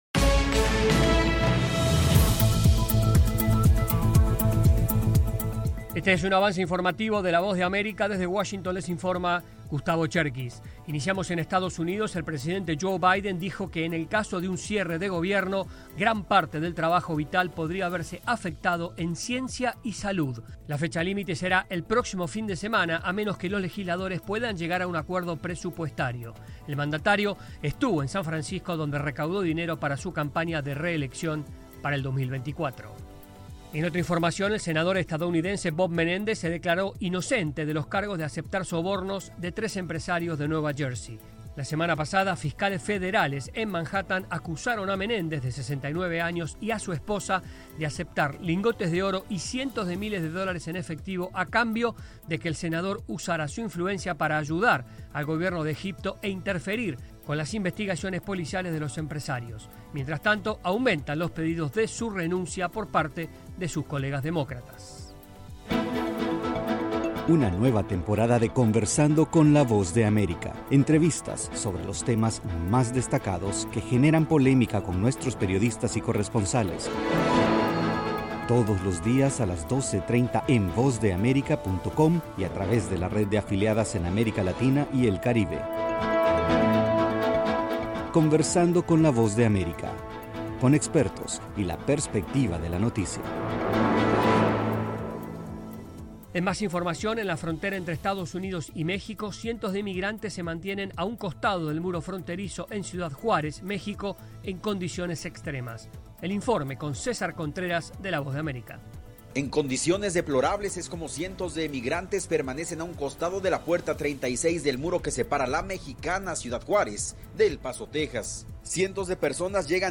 Este es un avance informativo de la Voz de América.